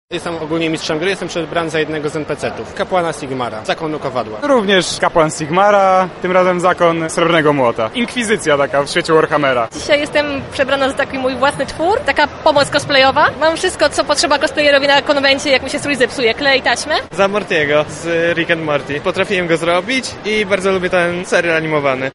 Wymyślnie przebranych uczestników o to kim są zapytała nasza reporterka